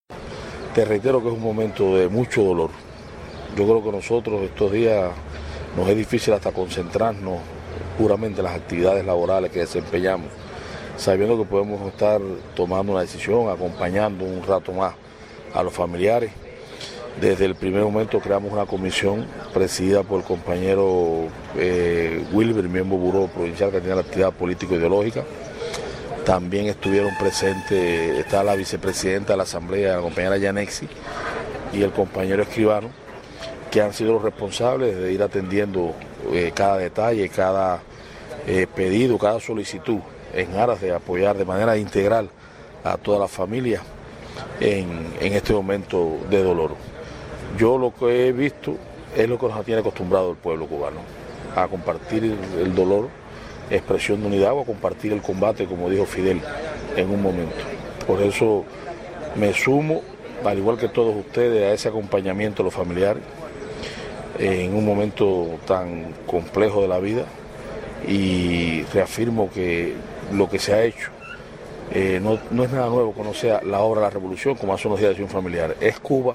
A rendirles postrer tributo acudieron, de inmediato, familiares, amigos, vecinos y las máximas autoridades políticas y gubernamentales en la provincia de Granma y el municipio de Bayamo.
Palabras-de-Federico-Hernández-Hernández.mp3